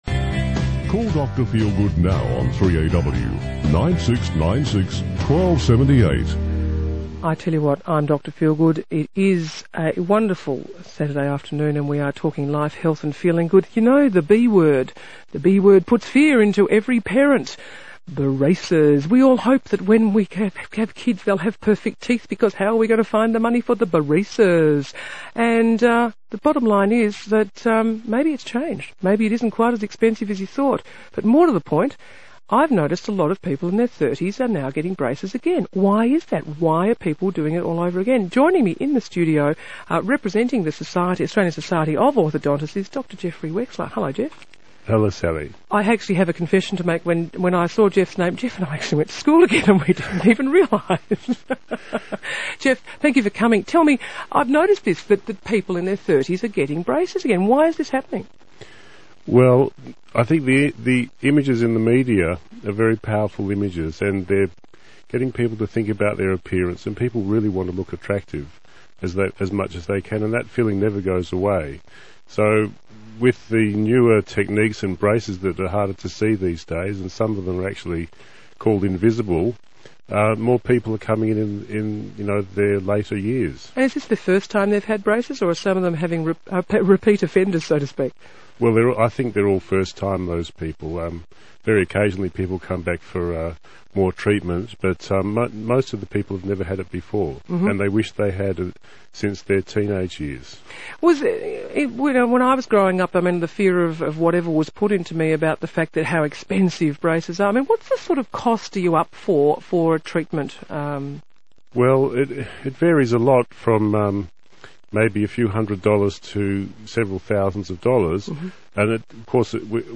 interviews an orthodontist
Life, Health and Feeling Good (Radio - 3AW Melbourne)